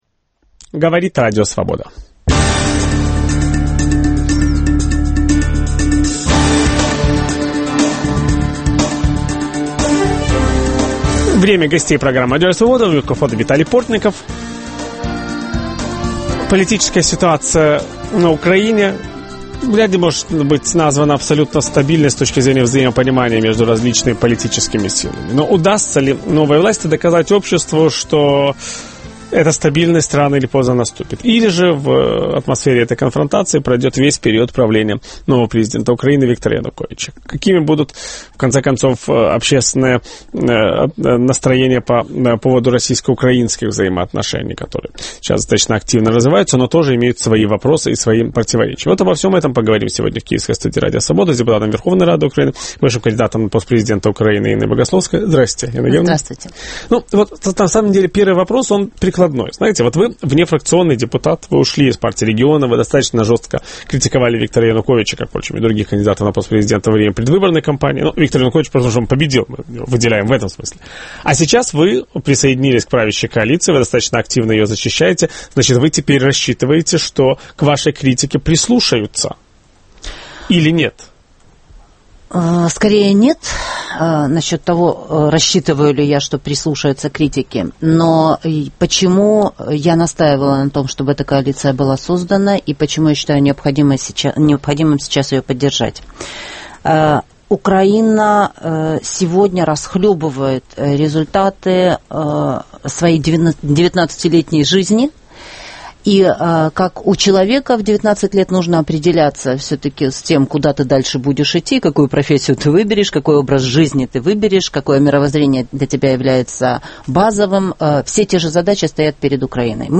Каковы намерения украинской власти? В программе участвует депутат Верховной рады Украины Инна Богословская.